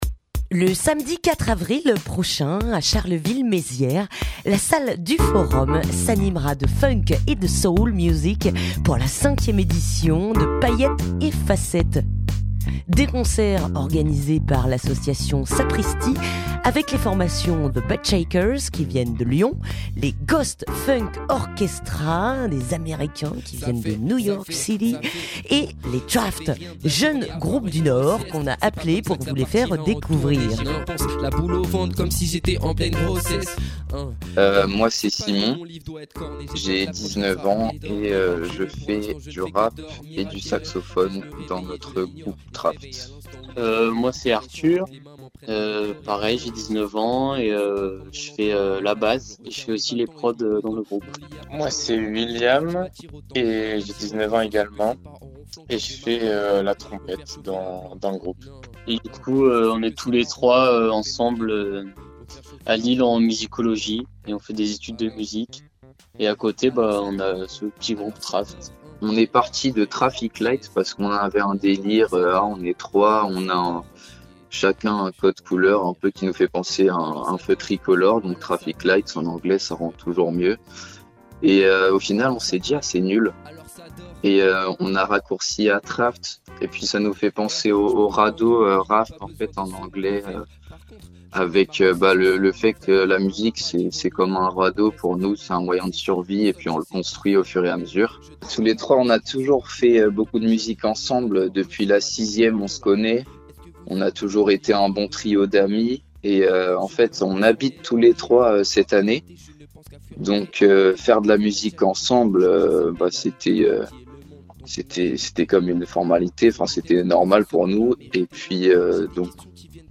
Radio Primitive ouvre ses micros au groupe Traft pour quelques présentations.